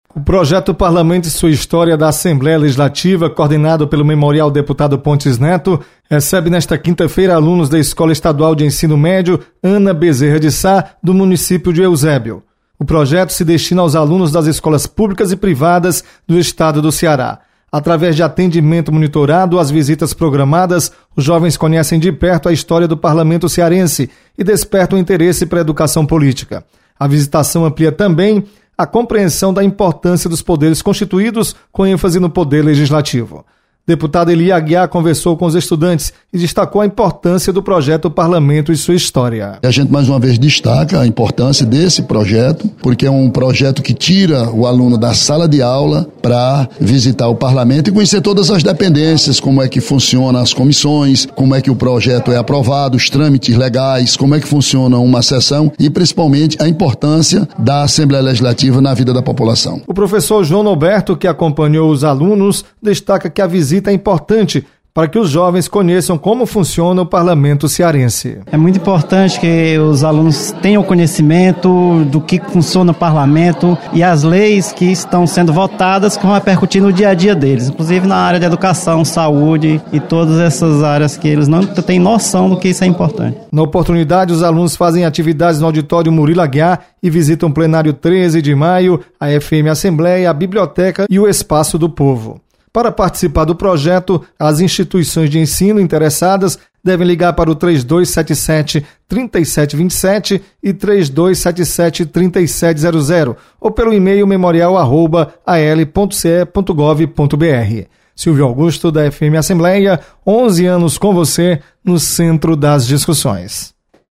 Memorial Pontes Neto recebe alunos no Programa Parlamento e Sua História. Repórter